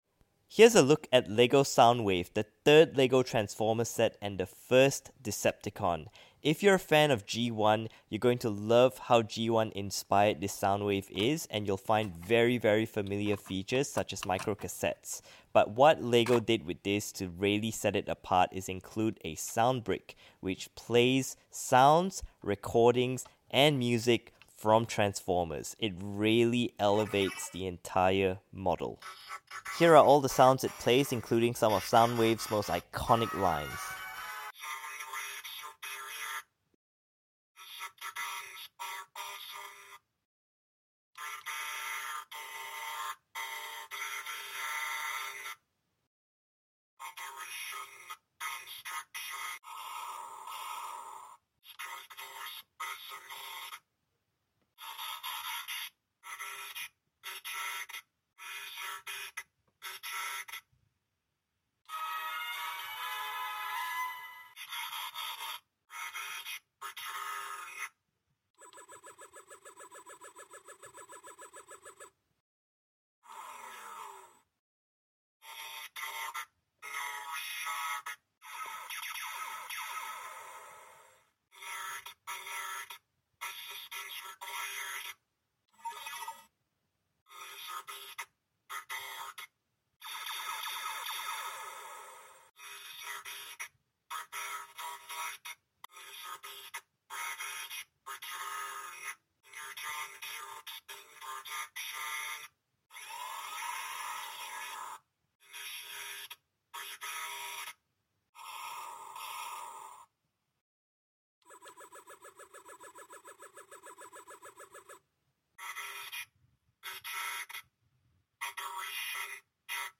Here are all of LEGO sound effects free download
Here are all of LEGO Soundwave sounds that I managed to record! This might be the best Transformers Soundwave model ever (until Robosen make one that also doubles as a Bluetooth speaker) and it's just so much fun!